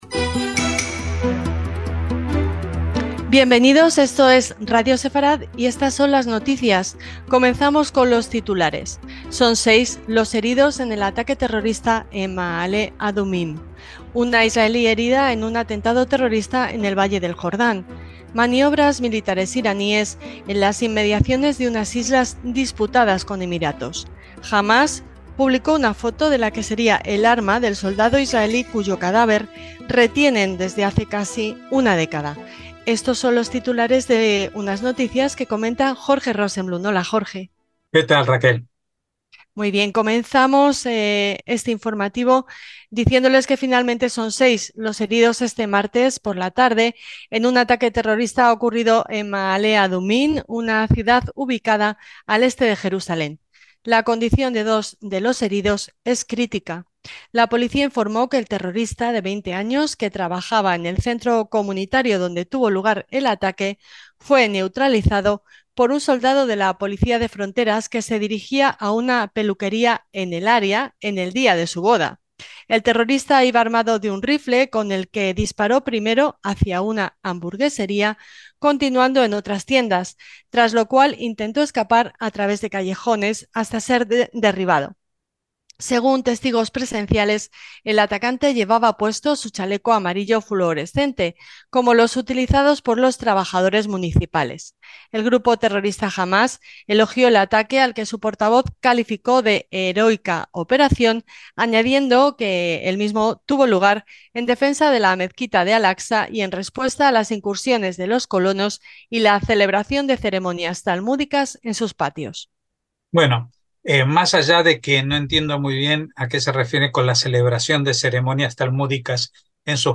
NOTICIAS – Titulares de hoy: Son seis los heridos en el ataque terrorista en Maalé Adumím. Una israelí herida en un atentado terrorista en el Valle del Jordán. Maniobras militares iraníes en las inmediaciones de unas islas disputadas con Emiratos.